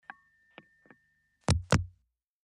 British telephone distant end hang - up ( early 1960`s )